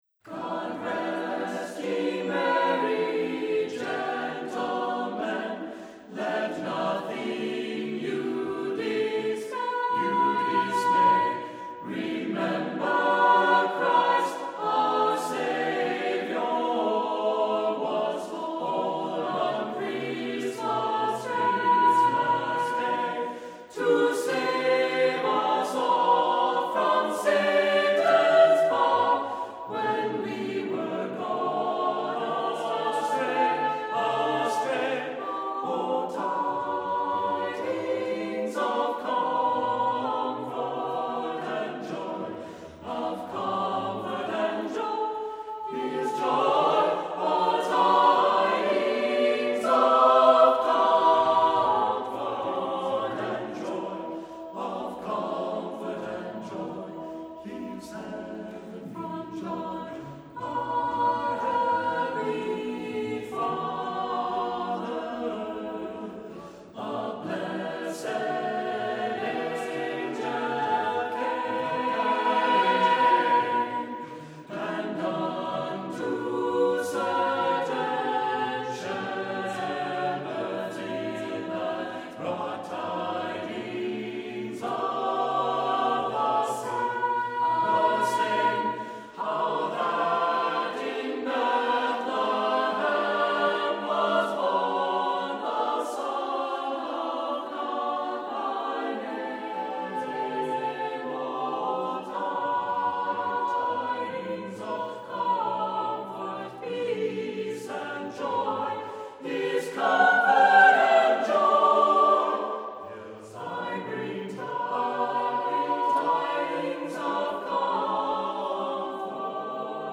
Accompaniment:      A Cappella
Music Category:      Vocal Jazz